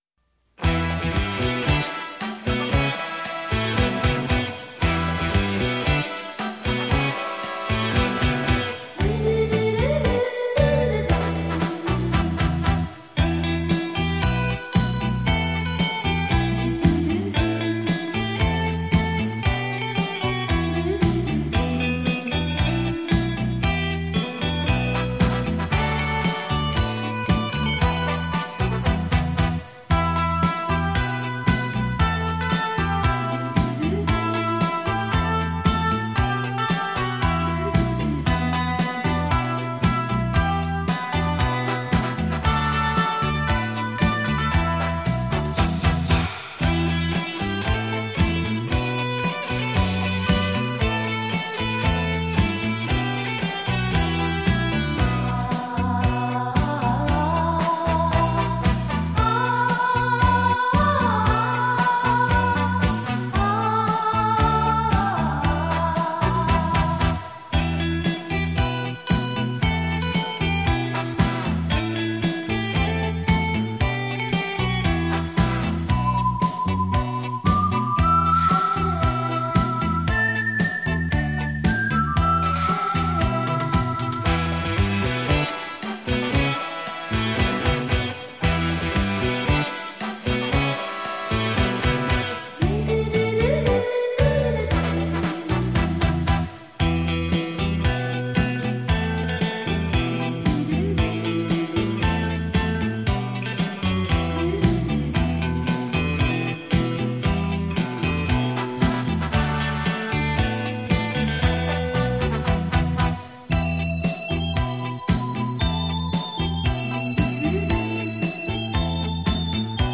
身历其境的临场效果